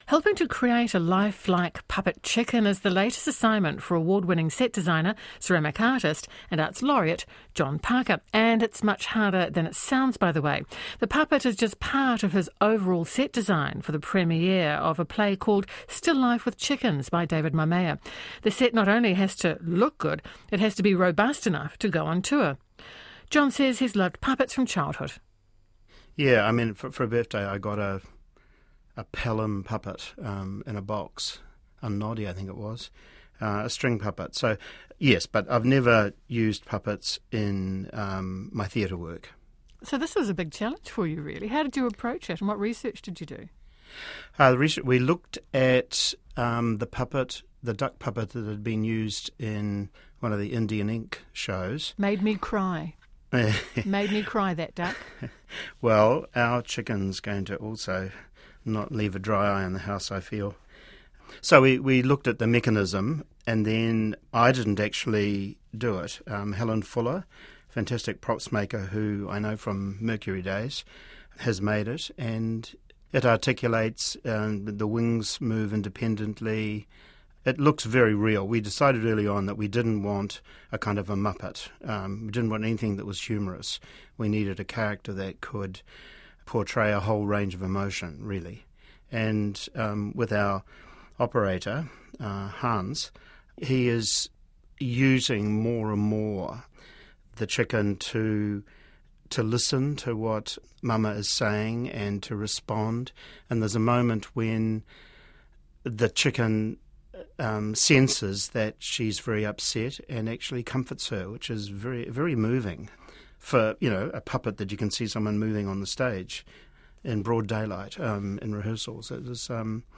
interview on Standing Room Only